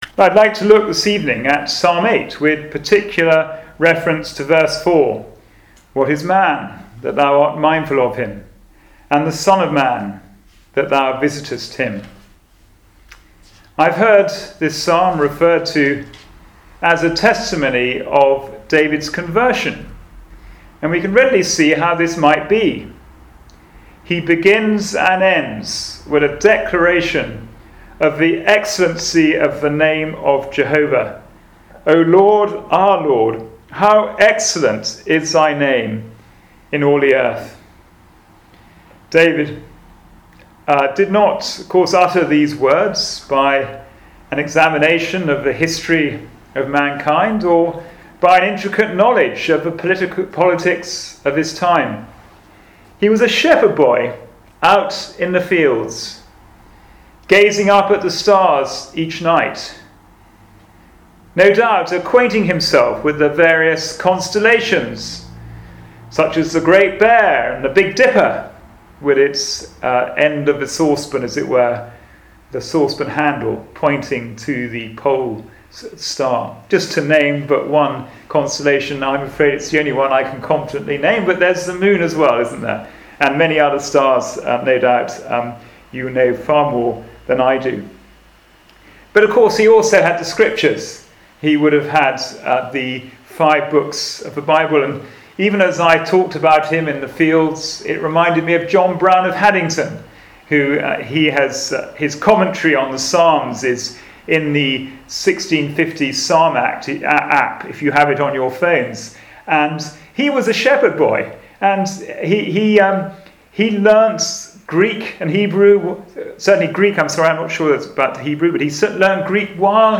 Passage: Psalm 8 Service Type: Sunday Evening Service